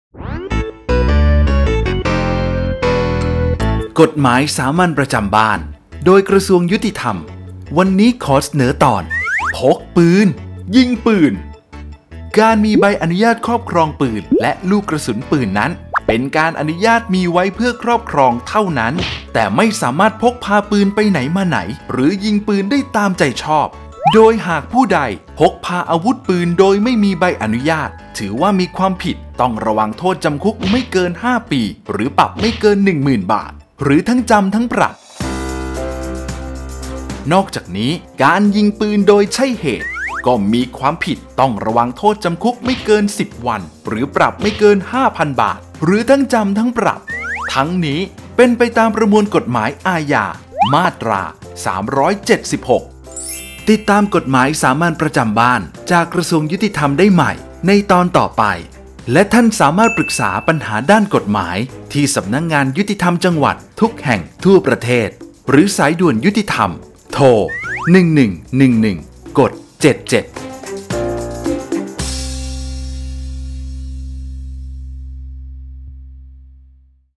กฎหมายสามัญประจำบ้าน ฉบับภาษาท้องถิ่น ภาคกลาง ตอนพกปืน ยิงปืน
ลักษณะของสื่อ :   คลิปเสียง, บรรยาย